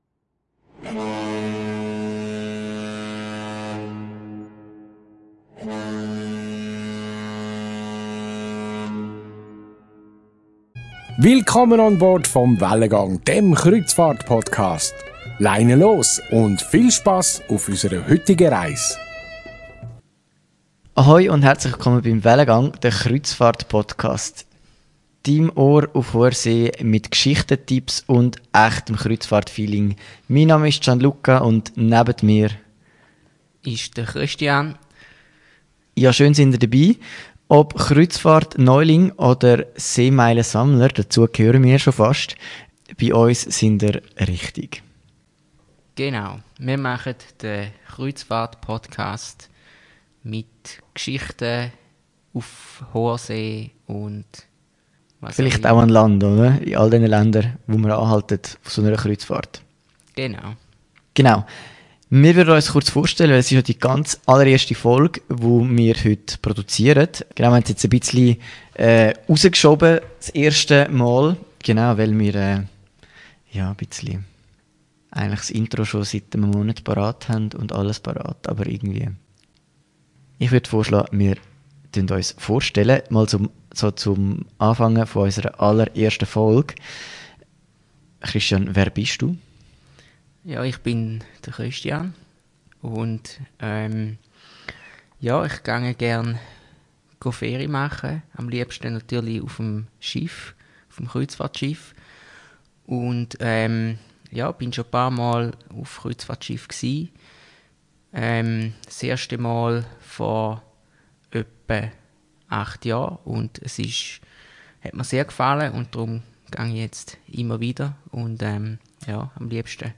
In der ersten Episode von Wellengang – Der Kreuzfahrtpodcast stellen wir uns vor – zwei Kreuzfahrt-Liebhaber, die dir alles zeigen, was du über das Reisen auf dem Wasser wissen musst. In dieser Folge plaudern wir aus dem Nähkästchen und erzählen dir, wie wir die Leidenschaft für Kreuzfahrten entdeckt haben und warum wir es lieben, die Welt auf einem Schiff zu erkunden.